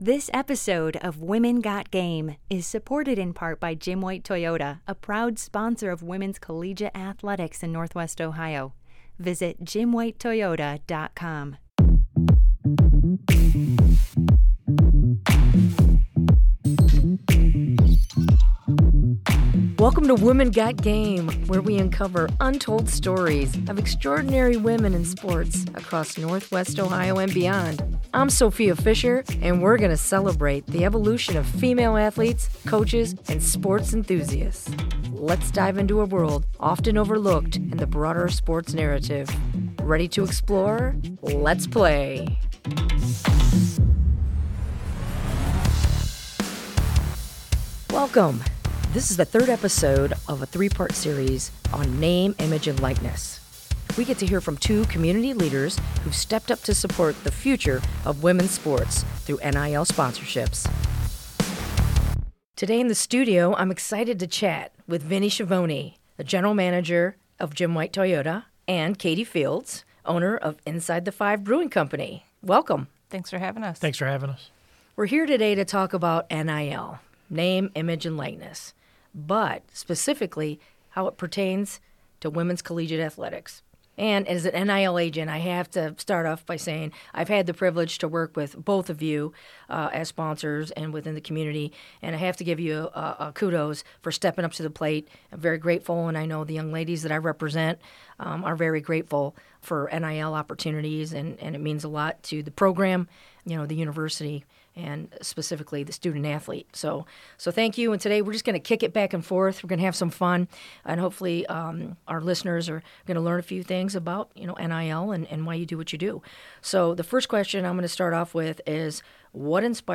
Supporting Female Athletes in the NIL Era: A Conversation with Sponsors - WGTE Public Media